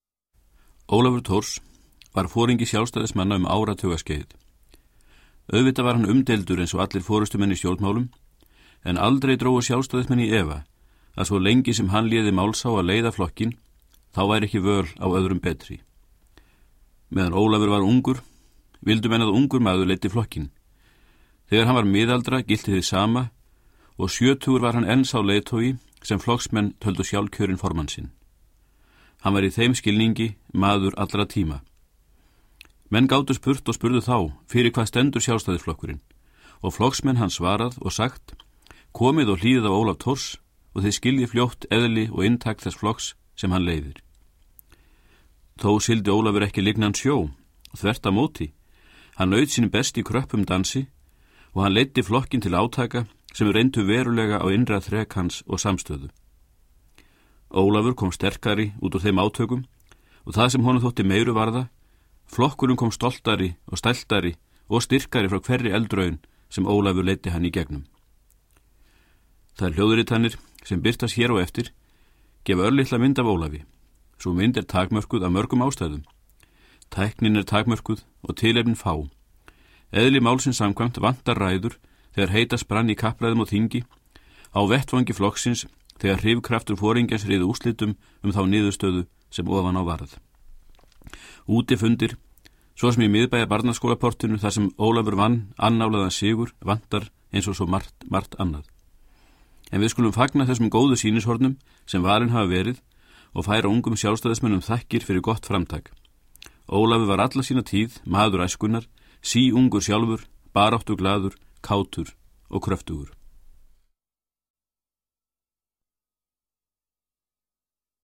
Hljómplatan úr umslagi, hlið 1.
Ríkisútvarpið afritaði plöturnar á CD fyrir Borgarskjalasafn 2008.